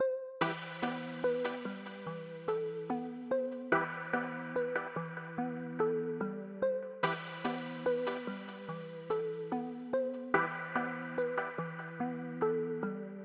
标签： 145 bpm Trap Loops Synth Loops 2.23 MB wav Key : E FL Studio
声道立体声